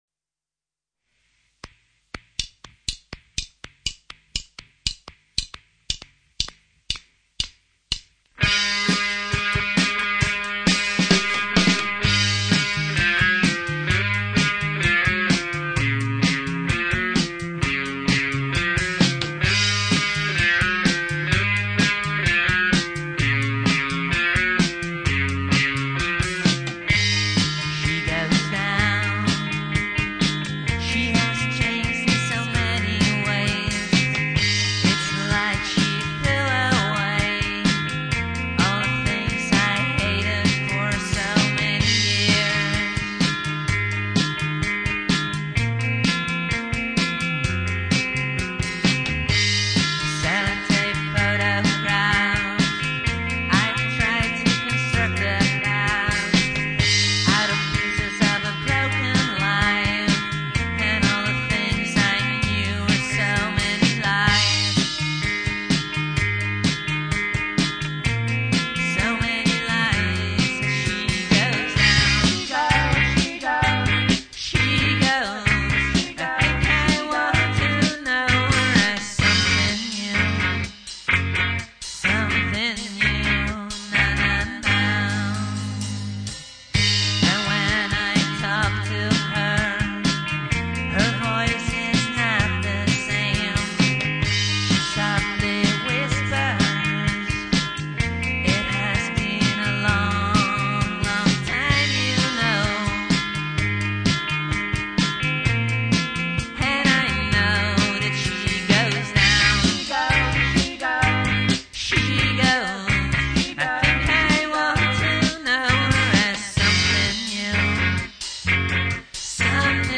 where: recorded at CMA (Amsterdam)
trivia: unique start, and accelerated to ducksound